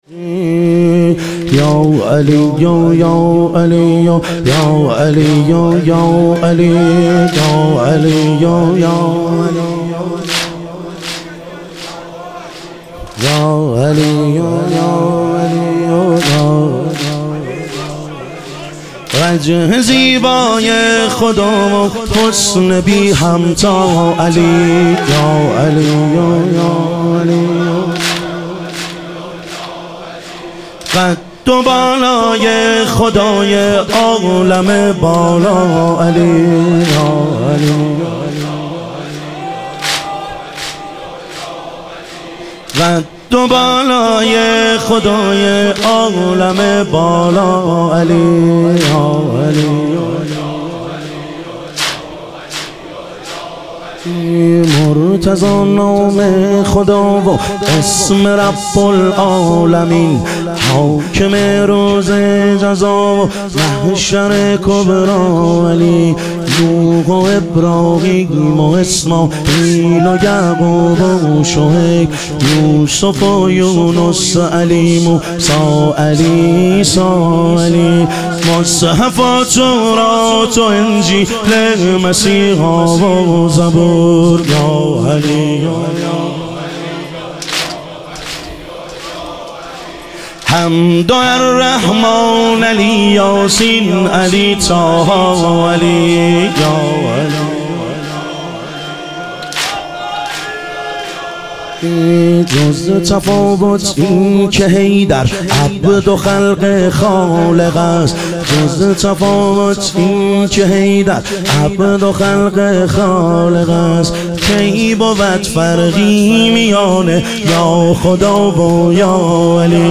محرم الحرام - واحد